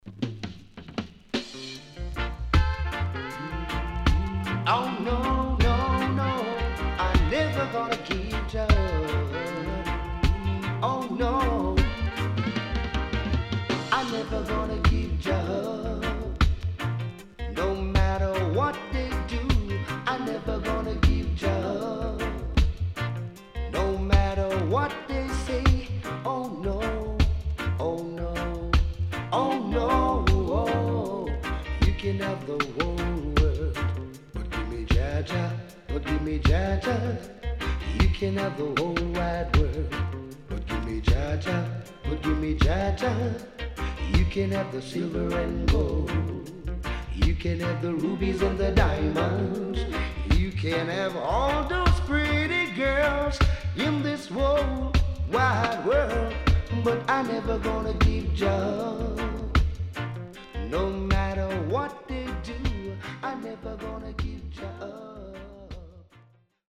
riddim
SIDE A:少しチリノイズ入りますが良好です。